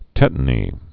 (tĕtn-ē)